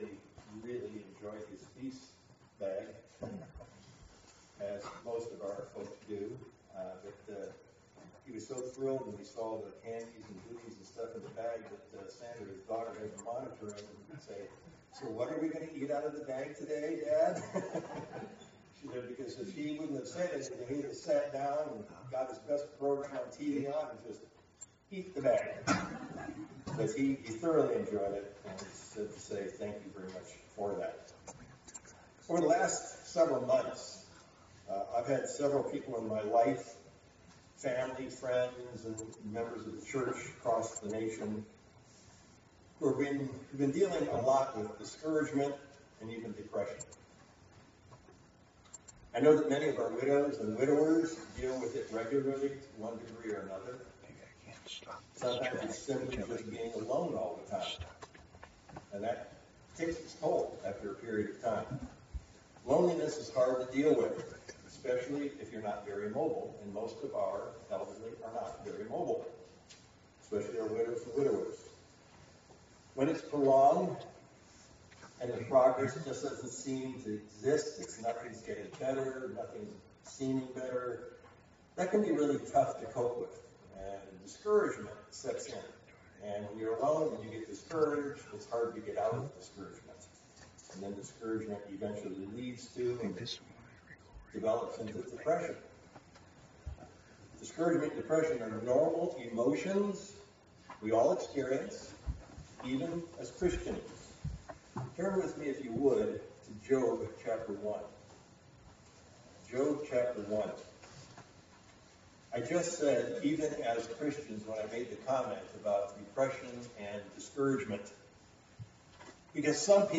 Given in Sacramento, CA
View on YouTube UCG Sermon Studying the bible?